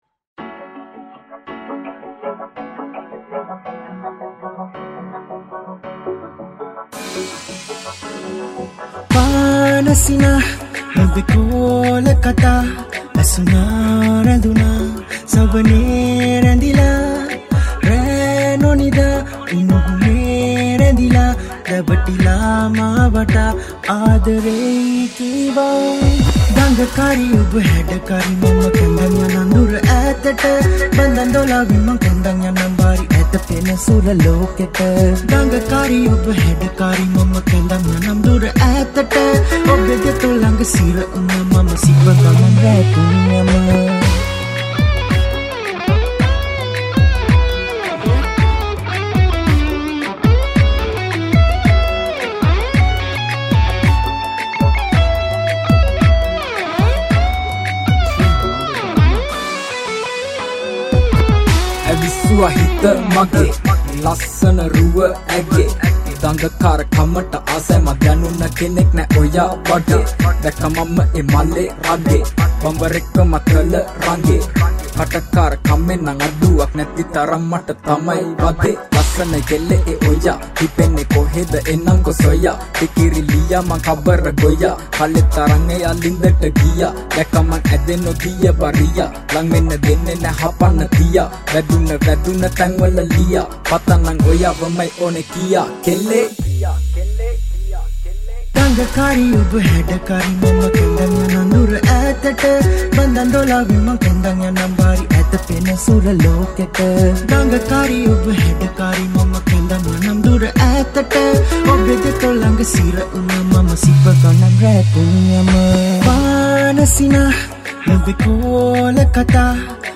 lead Guitar
Category: Rap Songs